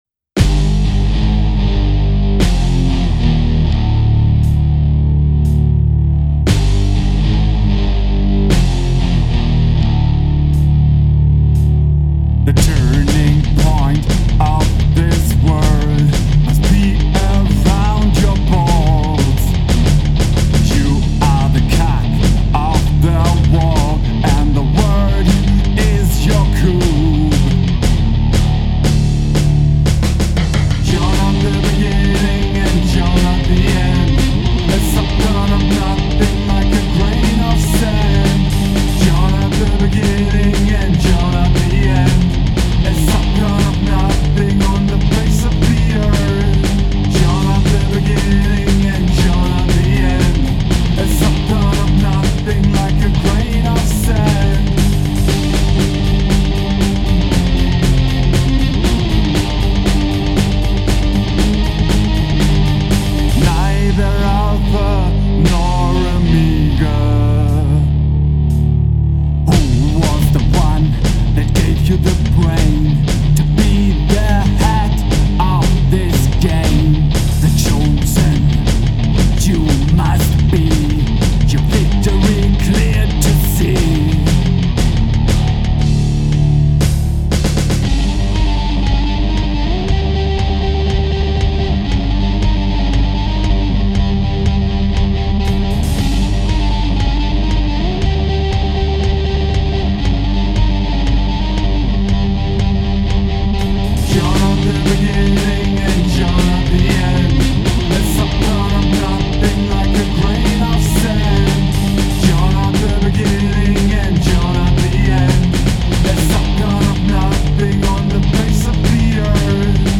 Unsere Musik ordnen wir als Rock mit englischen Texten ein.
Gitarre
Bass
Gesang/ Gitarre